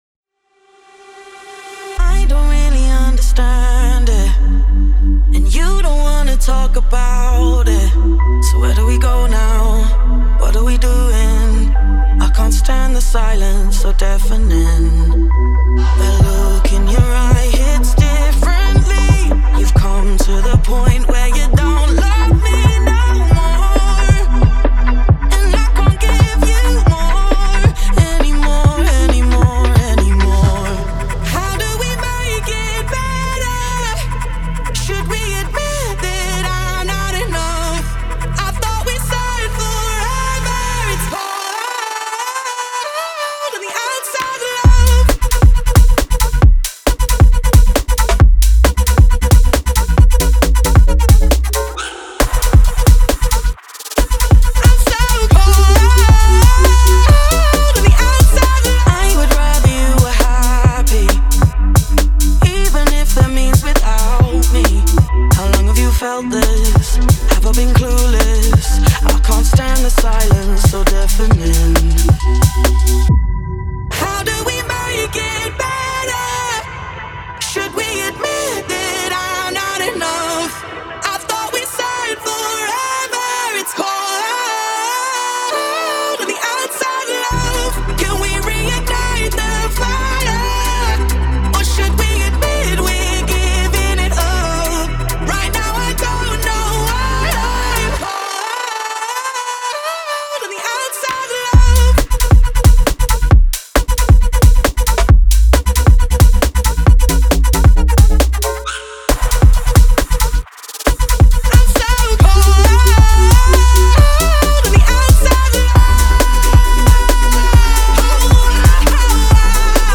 • Жанр: Dance